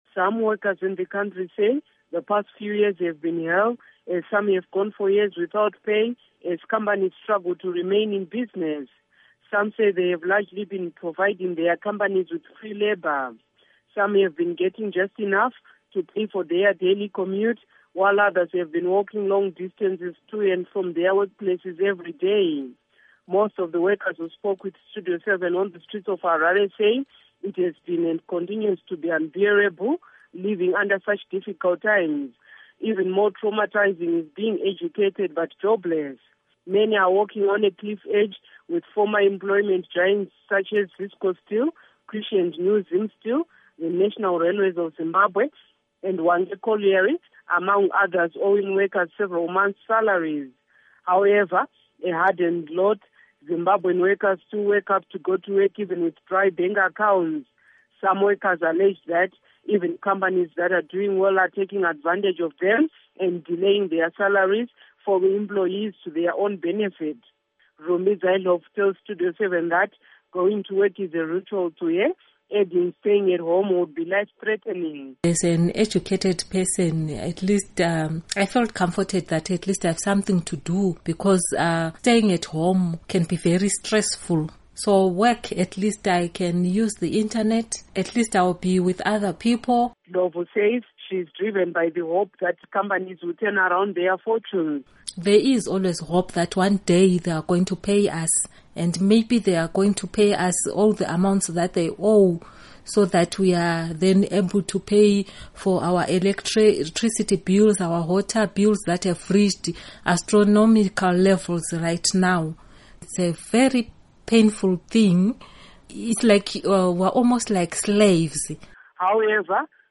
Report on Zimbabweans' Livelihoods